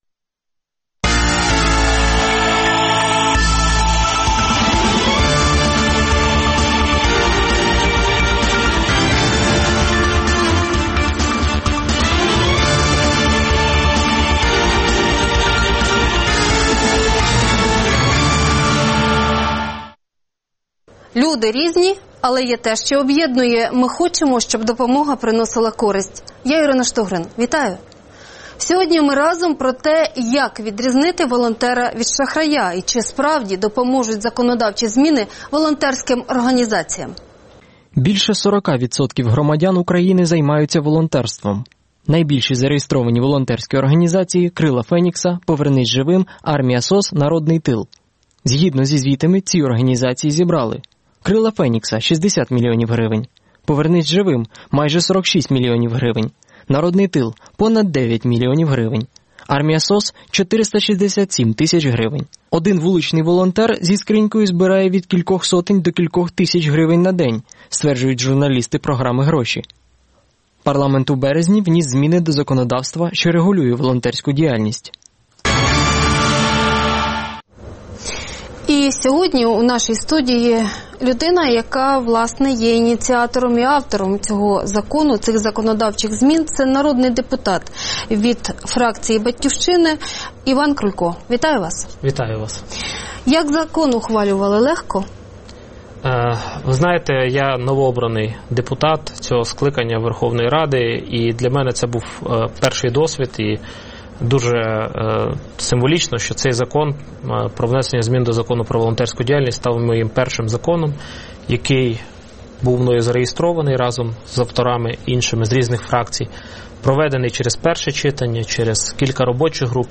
Гість: Іван Крулько, народний депутат, один із ініціаторів законодавчих змін із регулювання законодавчої діяльності.